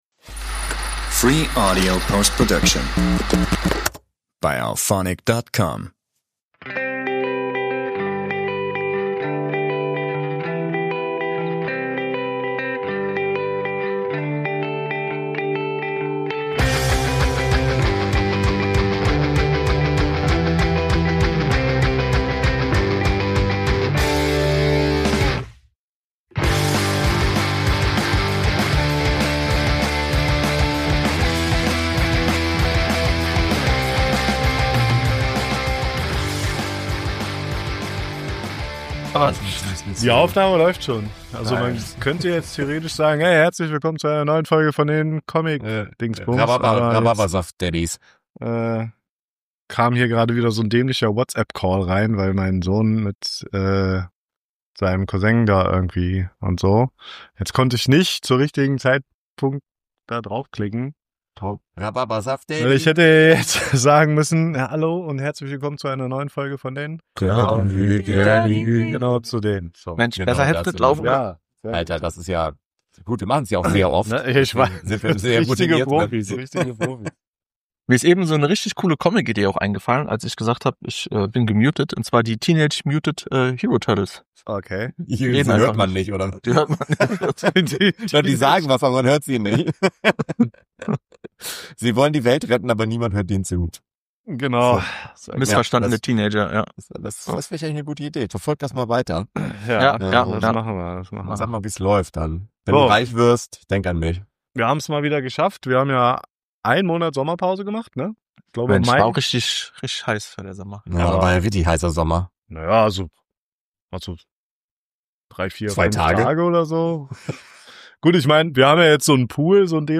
Die Daddies melden sich aus der Sommerpause zurück und haben Wurm in der Technik direkt mitgebracht. Trotz einiger Aussetzer und Stolpersteine haben die Jungs es mal wieder geschafft einen ganzen Haufen an Blödfug zusammen zuquatschen.